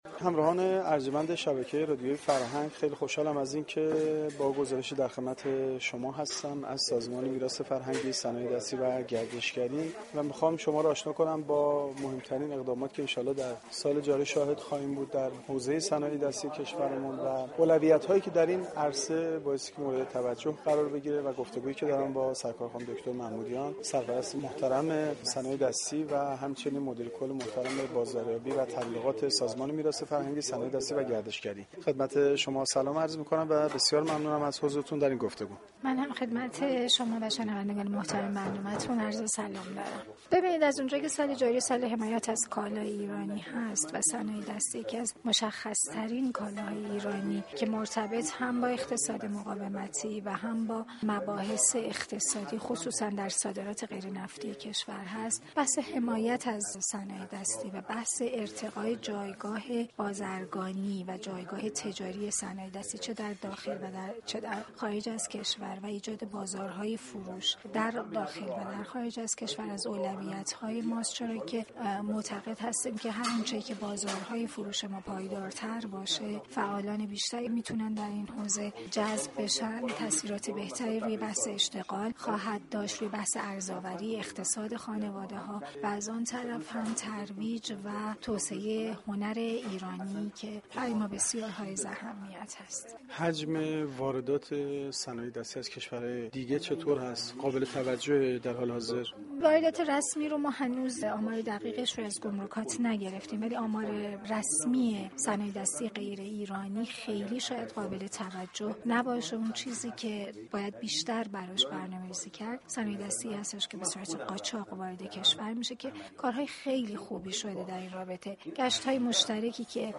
در گفتگوی اختصاصی با گزارشگر رادیو فرهنگ